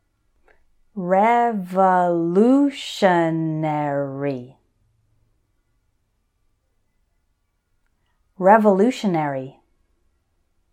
So for these words I’ll say them once slowly and once normally, so you can repeat both times.
re – vo – LU – tion – a – ry………… revolutionary